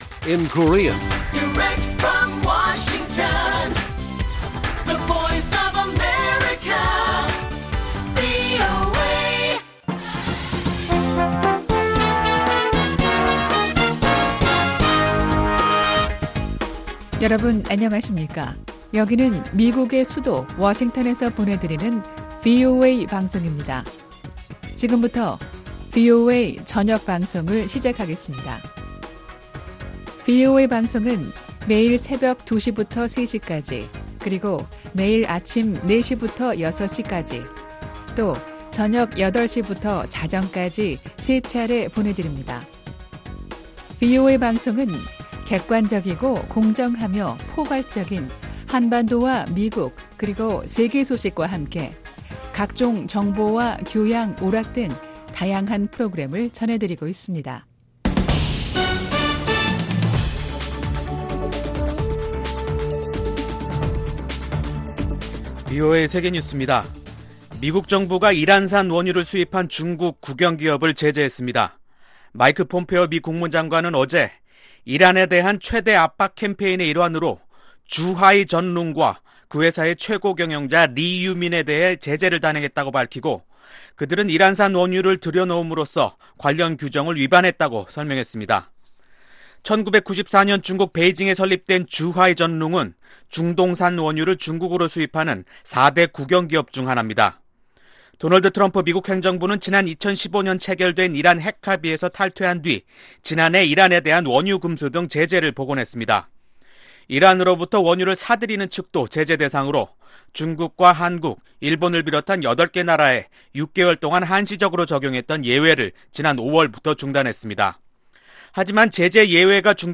VOA 한국어 간판 뉴스 프로그램 '뉴스 투데이', 2019년 7월 23일 1부 방송입니다. 마이크 폼페오 미 국무장관은 미국과 북한이 새로운 아이디어들을 갖고 대화에 나오길 바란다고 말했습니다. 마이크 폼페오 국무장관이 북한의 체제 안전보장에 대해 언급한 것은 북한을 대화 테이블로 이끌어내려는 노력의 일환이라고 전문가들은 평가했습니다.